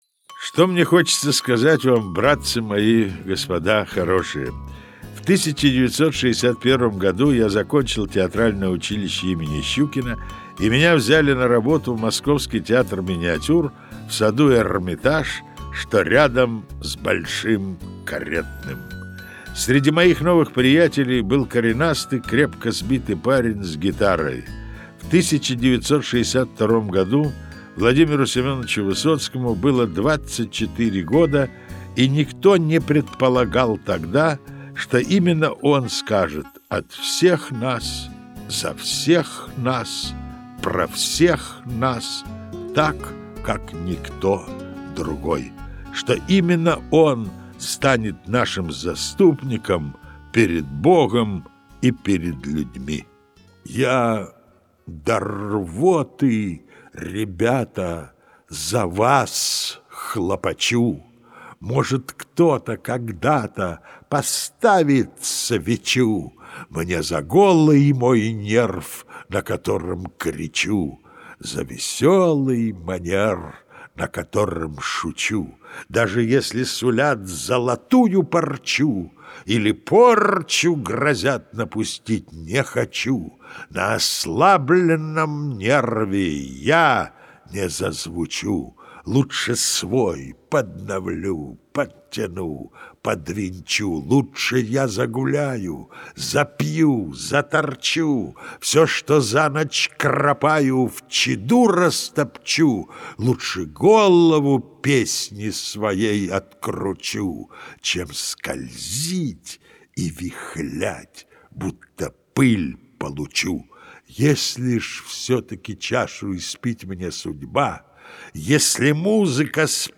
До спазма в горле, до мурашек на теле.
В альбоме Высоковского "Мой Высоцкий" записаны не только стихи, но и воспоминания. Рассказ об избранных встречах с Владимиром Высоцким, пунктиром прошедших через всю жизнь вплоть до кончины поэта.